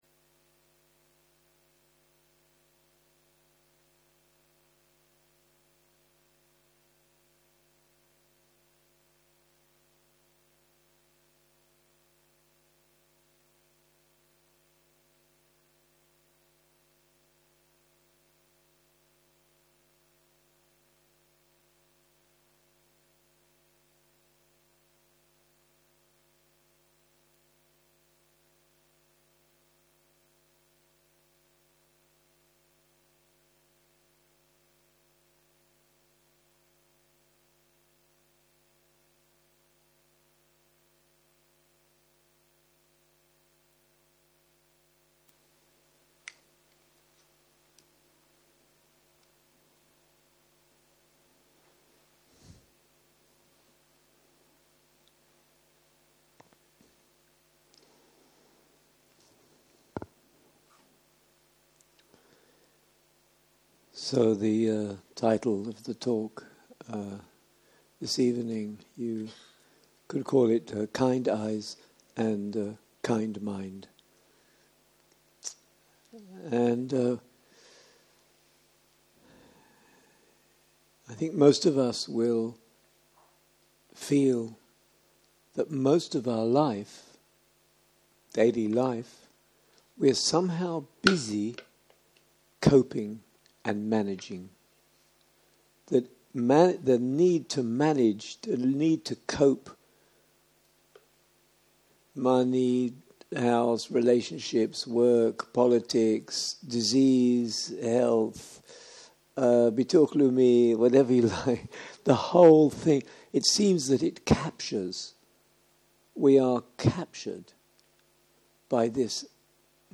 17.03.2023 - יום 3 - ערב - שיחת דהרמה - Kind eyes and Bright mind - הקלטה 7